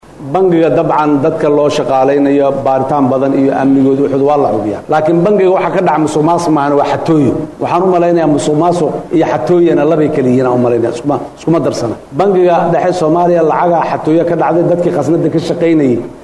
Cod-Madaxweynaha-1.mp3